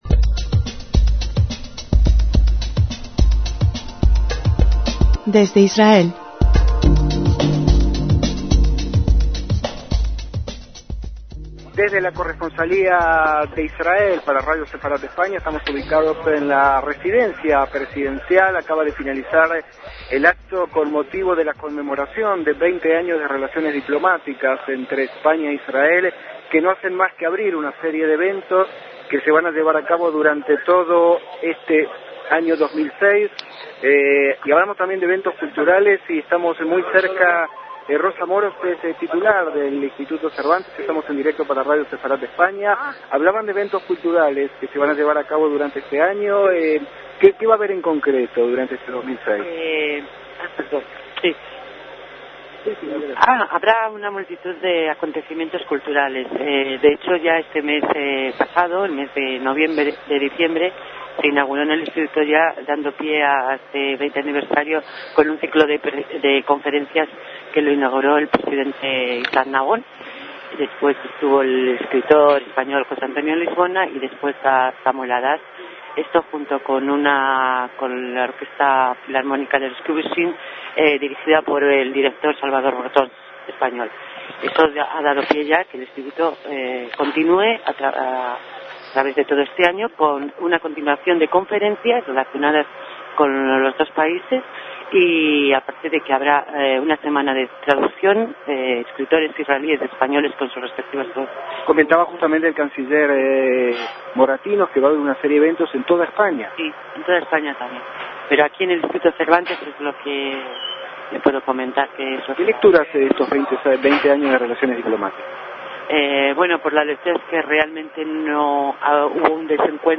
DECÍAMOS AYER (18/1/2006) - El vigésimo aniversario de las relaciones diplomáticas entre Israel y España se conmemoró en ambos países con una serie de actos, como éste, cuyos ecos recogió Radio Sefarad de tres importantes inivtados a los fastos.